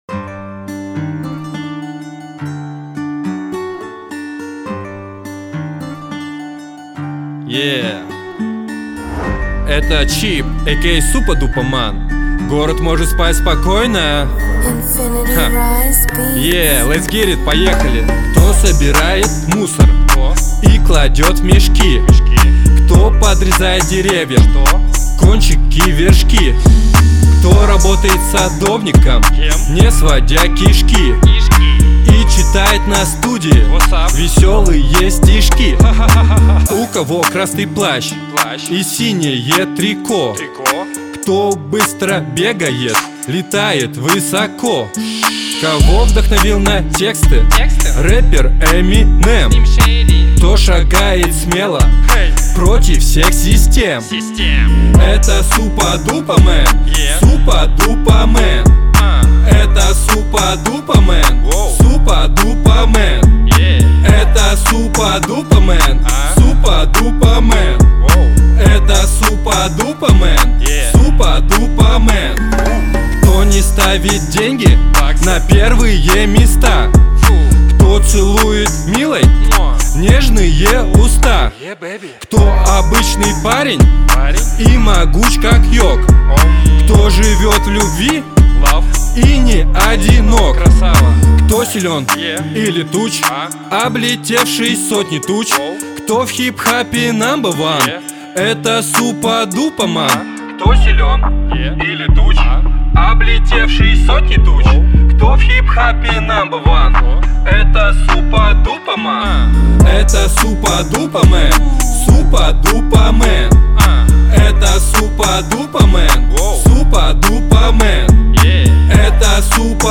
Мужской
Я выступаю с авторскими рэп-песнями, меня вдохновляет хип-хоп музыка.